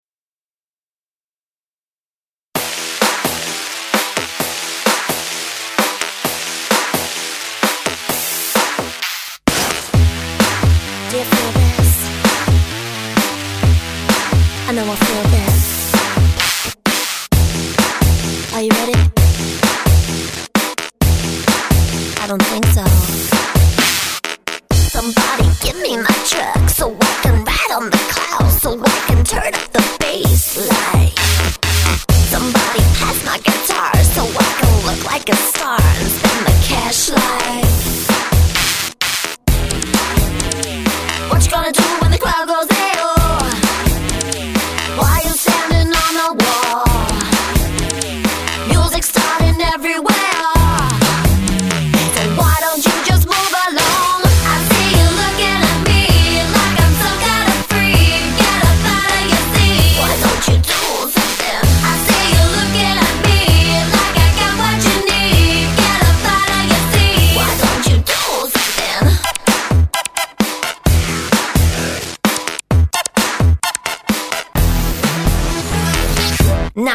High energy show with iconic pop hits